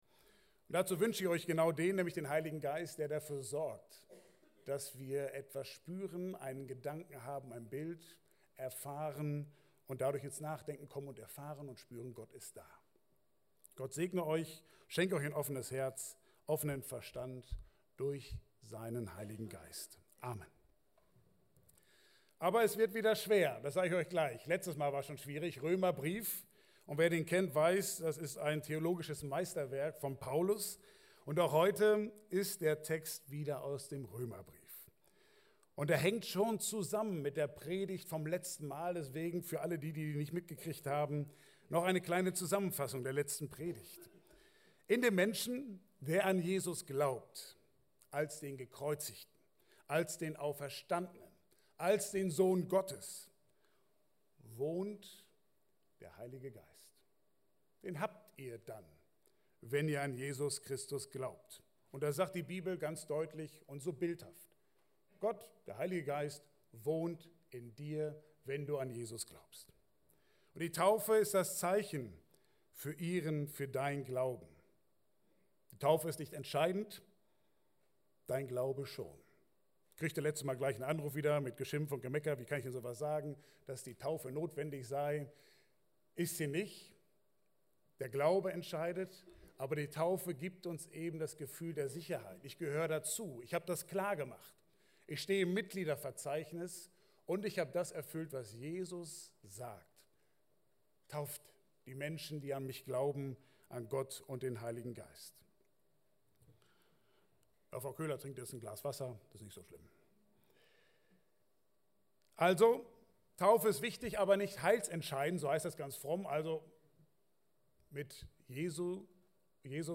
Passage: Römer 11, 32-36 Dienstart: Gottesdienst « Weil es sich lohnt